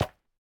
resin_brick_step4.ogg